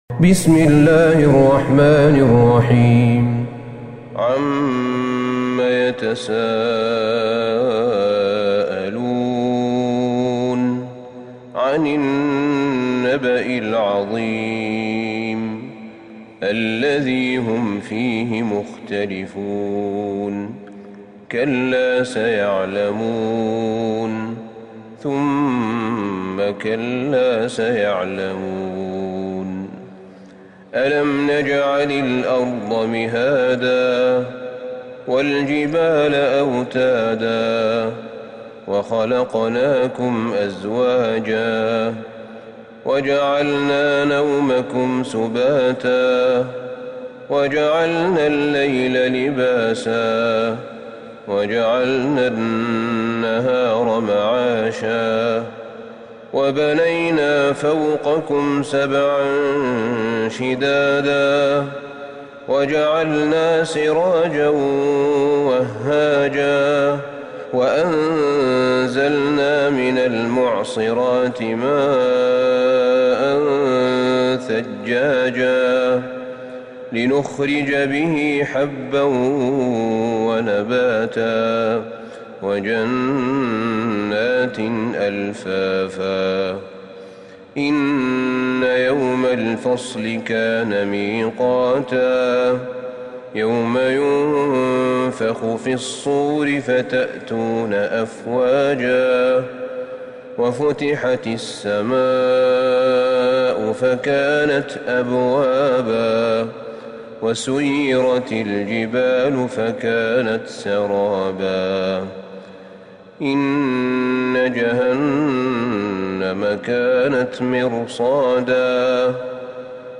سورة النبأ Surat An-Naba > مصحف الشيخ أحمد بن طالب بن حميد من الحرم النبوي > المصحف - تلاوات الحرمين